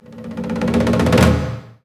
A#3 MDDRU01L.wav